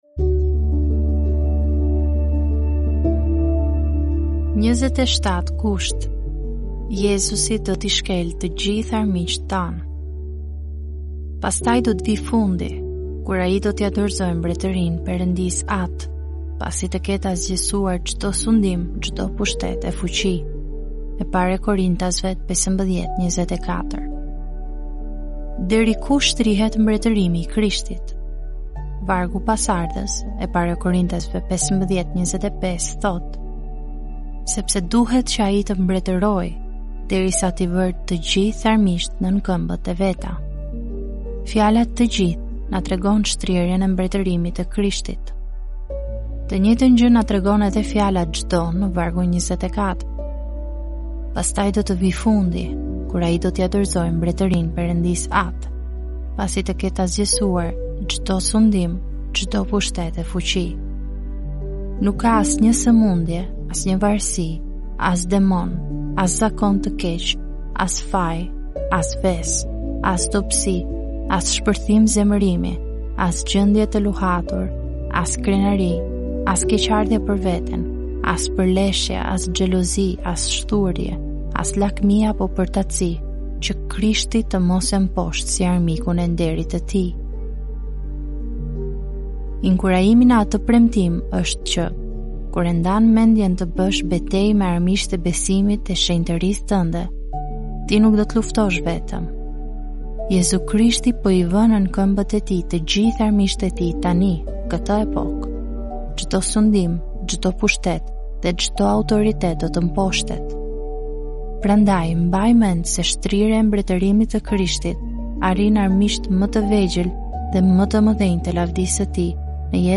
"Solid Joys" janë lexime devocionale të shkruara nga autori John Piper.